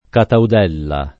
Cataudella [ kataud $ lla ] cogn.